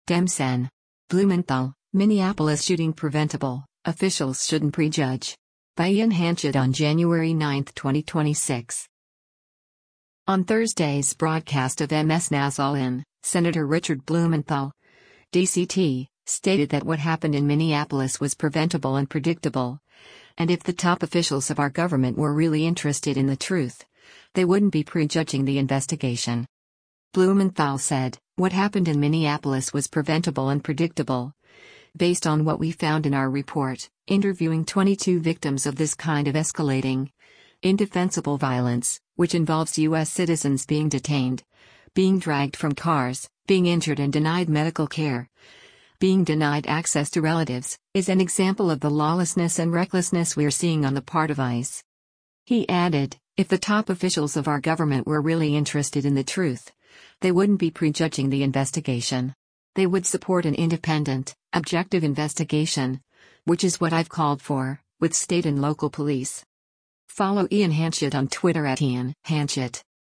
On Thursday’s broadcast of MS NOW’s “All In,” Sen. Richard Blumenthal (D-CT) stated that “What happened in Minneapolis was preventable and predictable,” and “If the top officials of our government were really interested in the truth, they wouldn’t be prejudging the investigation.”